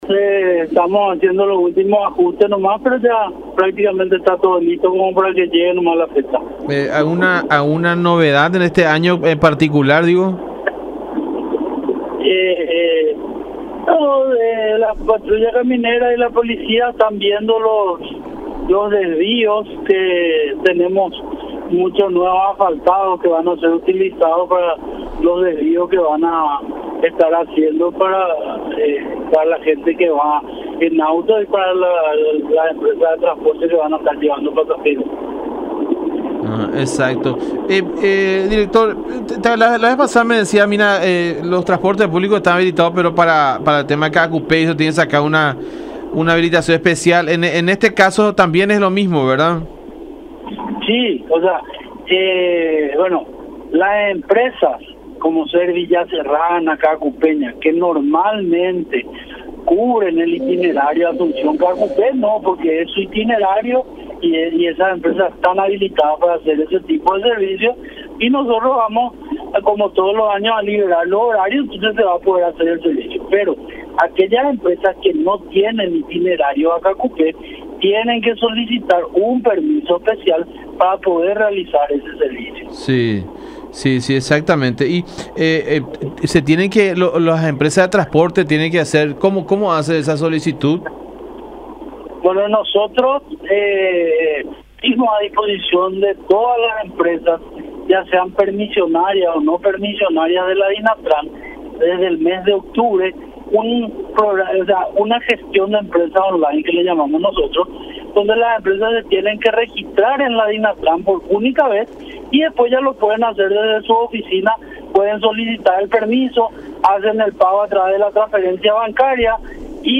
“Lo que estamos viendo es el uso de nuevos asfaltados”, manifestó Juan José Vidal, titular de la DINATRAN, en diálogo con La Unión indicando que estos caminos serán utilizados como desvíos para los fieles que irán en automóviles particulares y también en transporte público de pasajeros.